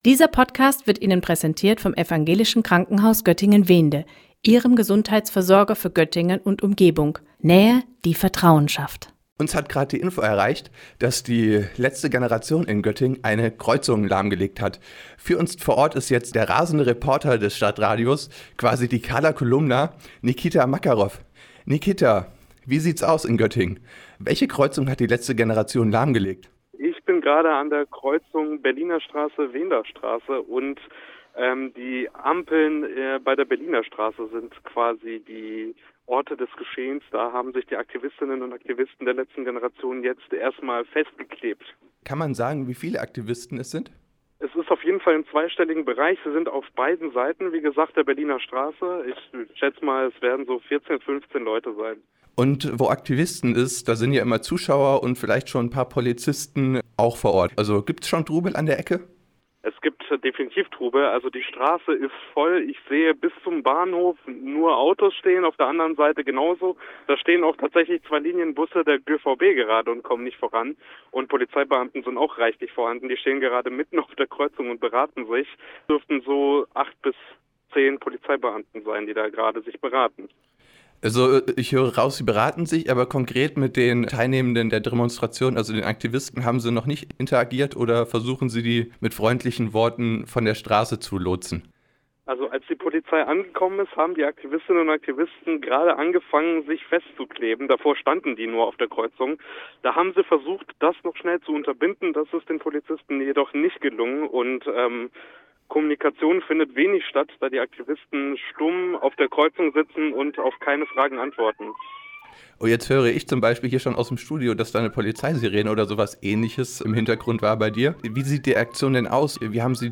TelefonschalteLetzeGeneration-playout.mp3